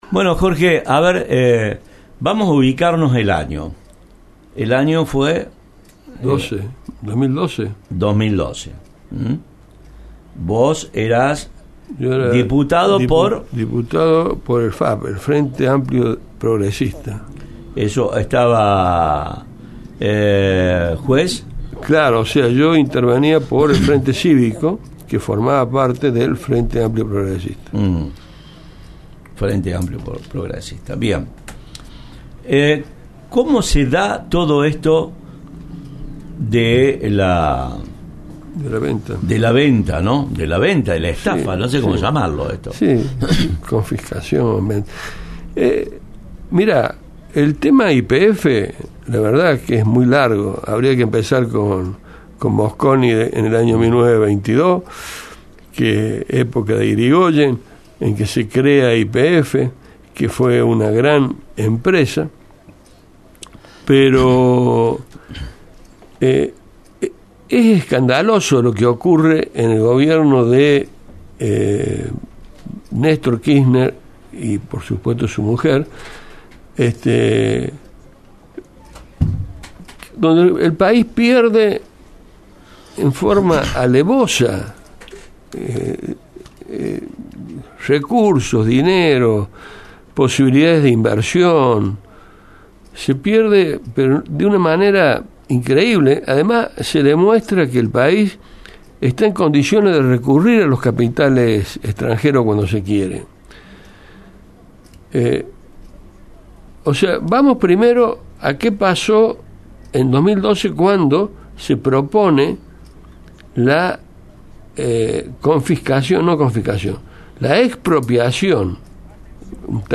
Y decimos inicialmente porque según lo que relató en el programa “ESCUCHÁNDONOS” de la Centro, el por entonces, (2012), diputado nacional, el Villamariense Dr. Jorge Anselmo Valinotto, existiría otra parte, un 18% que todavía no ha salido a la luz ni llevada a la Justicia de Estados Unidos.